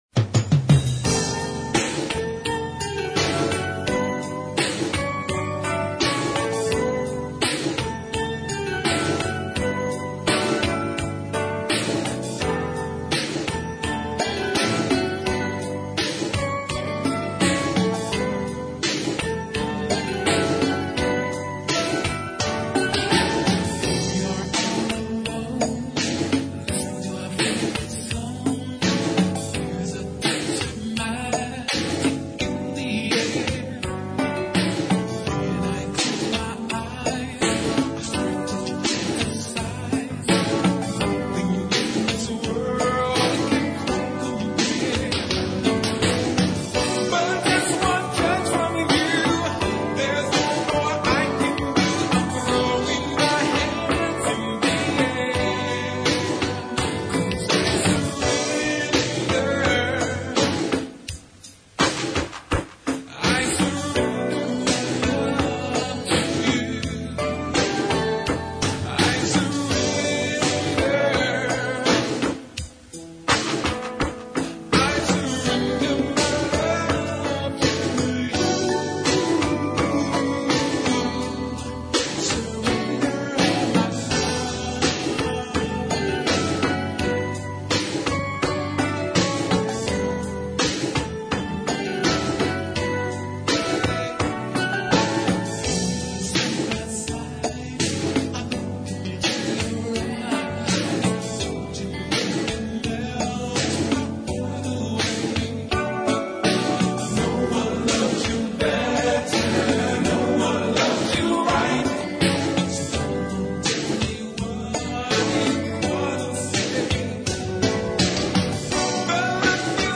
02 - SOUL